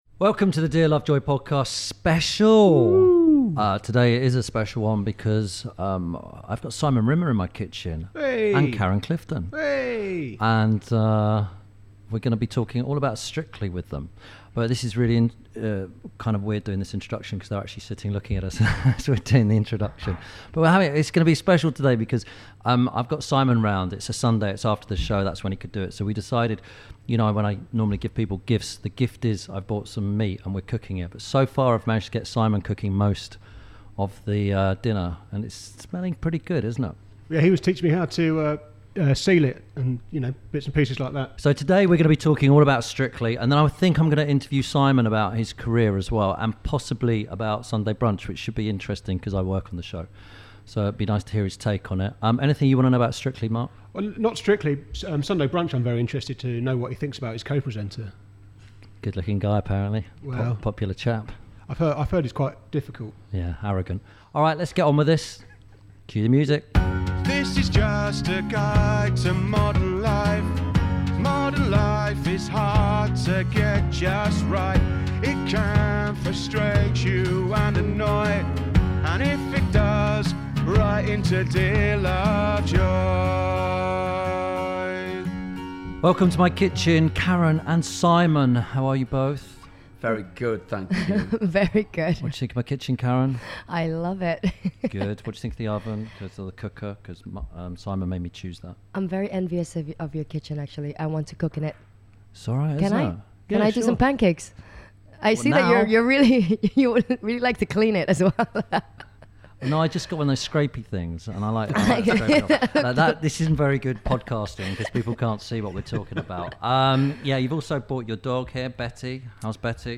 Ep. 49 – SIMON RIMMER AND KAREN CLIFTON – Karen And Simon Talk About Strictly – INTERVIEW SPECIAL.
This week Tim Lovejoy talks to Strictly Come Dancing professional Karen Clifton, and chef and fellow presenter of Sunday Brunch, Simon Rimmer. As well as discussing Strictly and Sunday Brunch, Tim gives them a dance lesson.